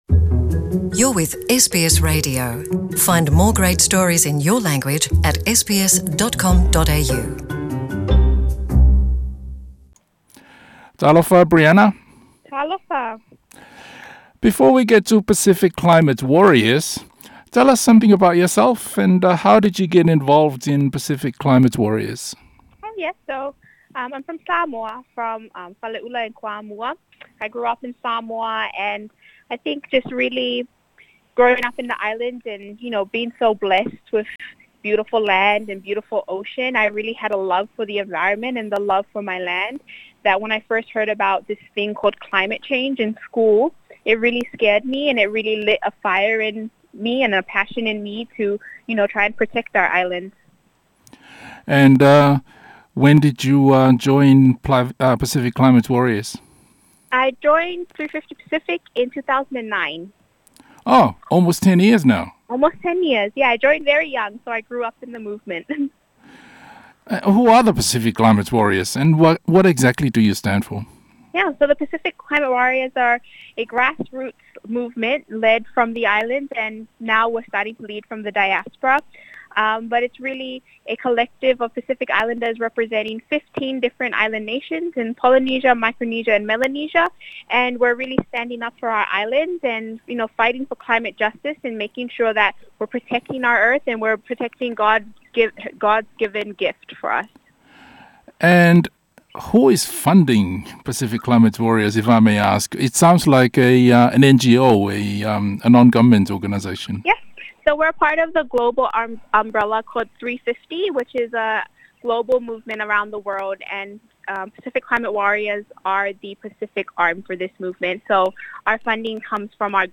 Se talanoaga